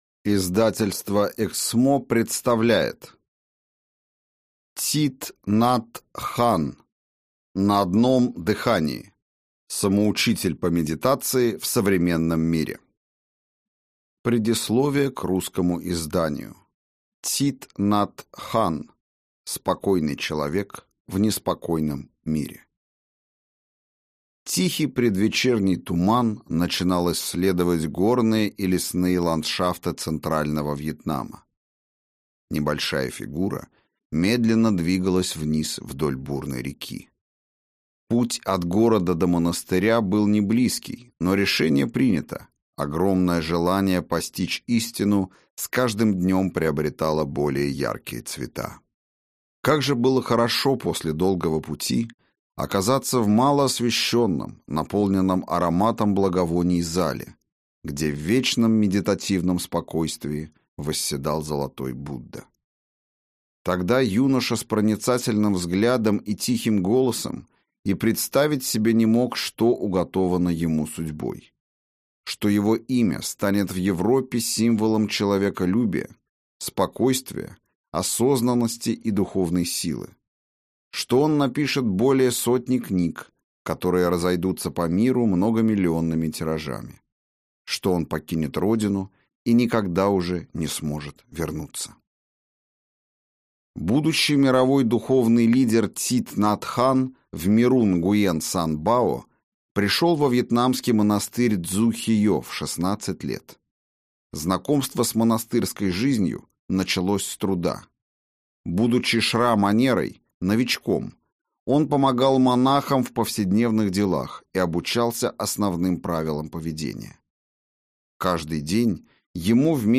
Аудиокнига На одном дыхании. Самоучитель по медитации в современном мире | Библиотека аудиокниг